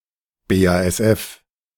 BASF SE (German pronunciation: [beːaːɛsˈʔɛf]